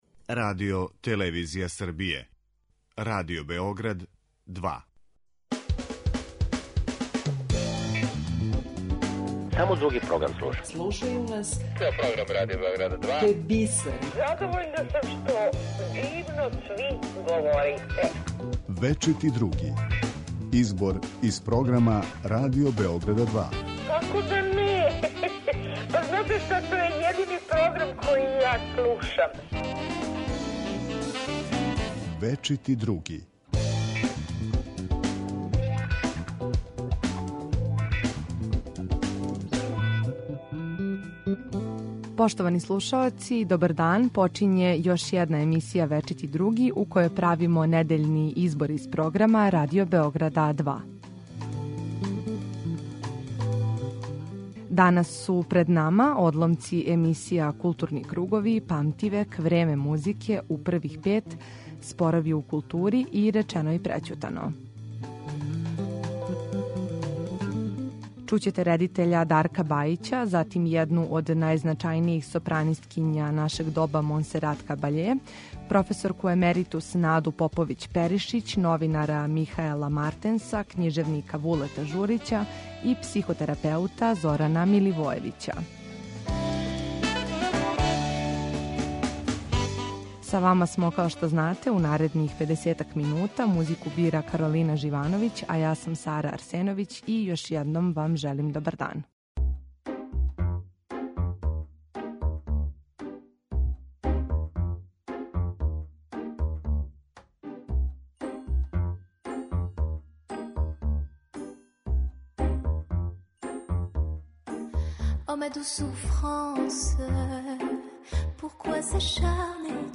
У емисији „Вечити други“ слушаоци ће моћи да чују избор најзанимљивијих садржаја емитованих на програму Радио Београда 2 током претходне и најаву онога што ће бити на програму идуће седмице.